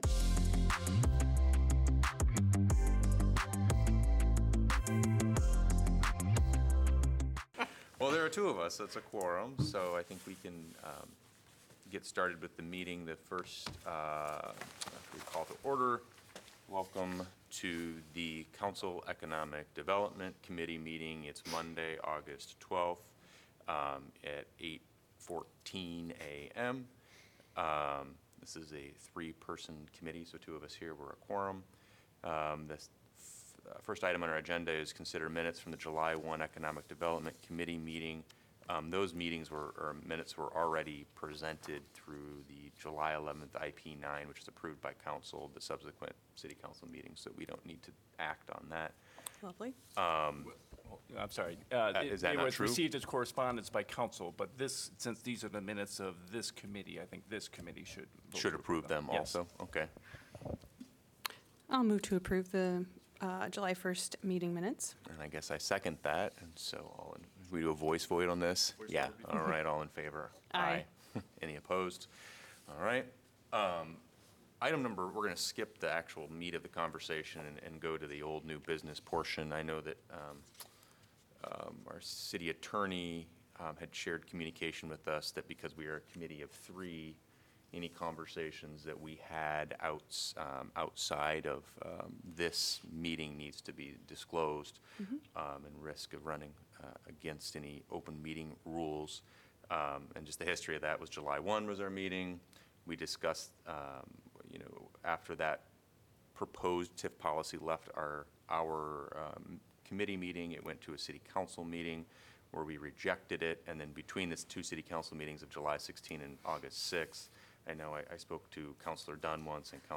A meeting of the Iowa City City Council's Economic Development Committee.